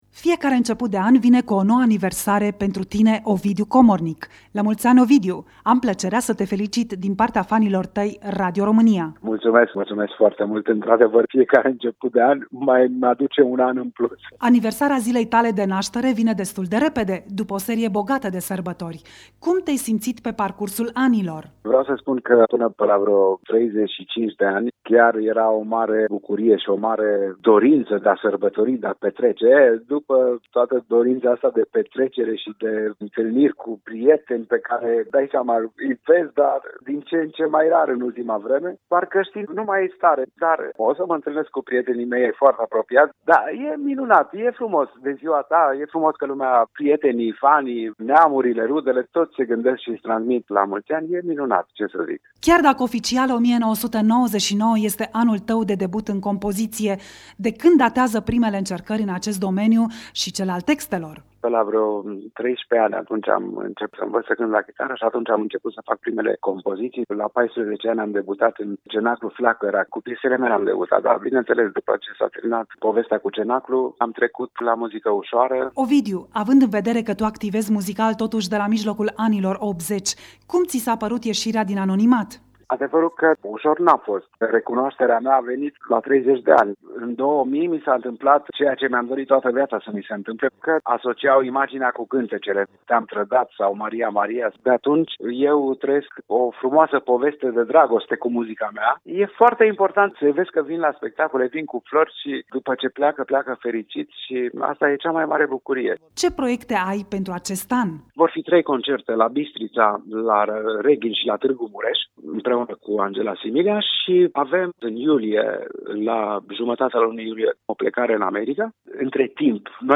Interviu Ovidiu Komornyik.